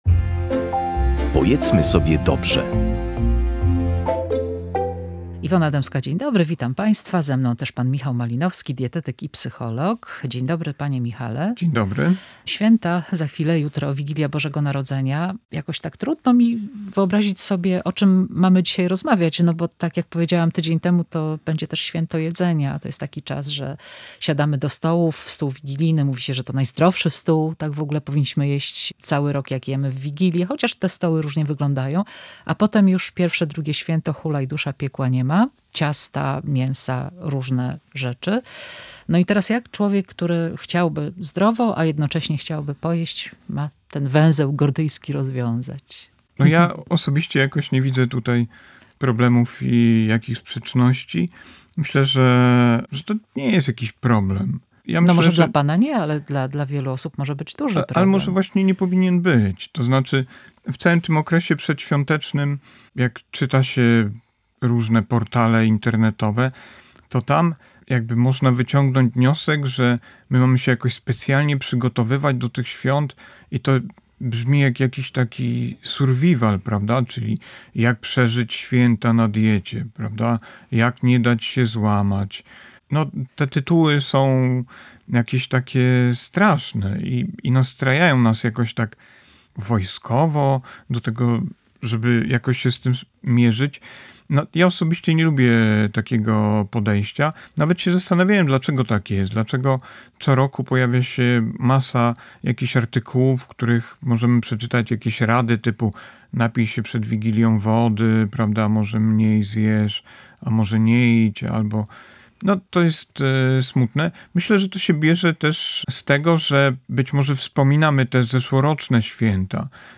rozmawiała z dietetykiem i psychologiem